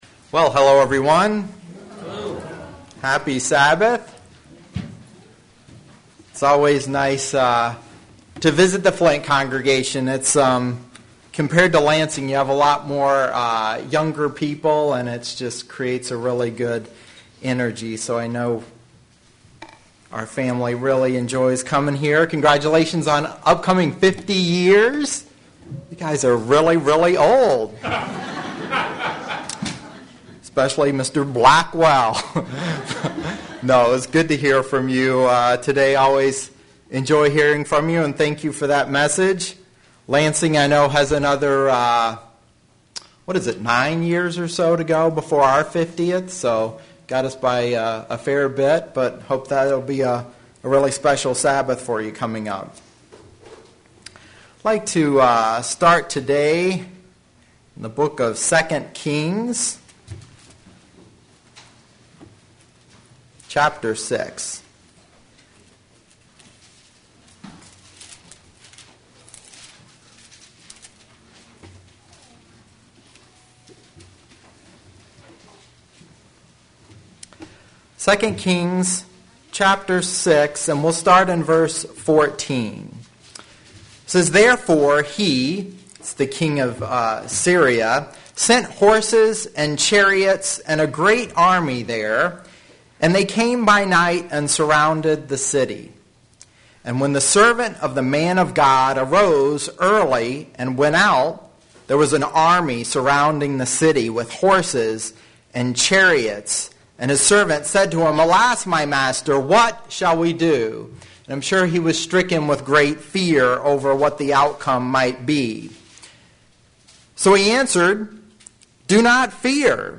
Get your answers here today. sermon Studying the bible?